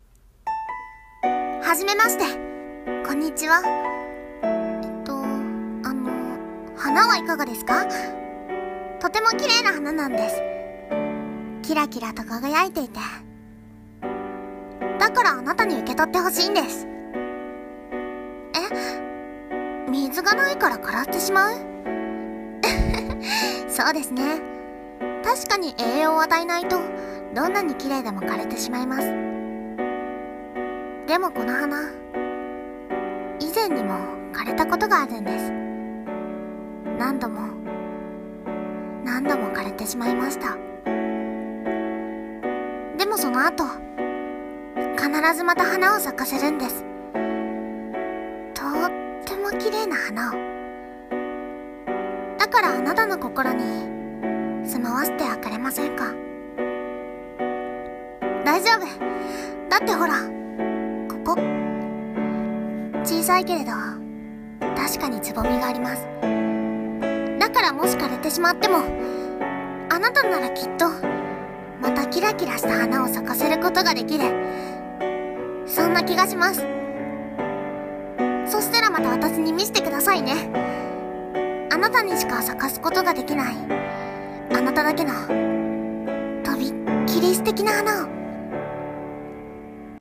【朗読台本】あなただけの花【一人声劇】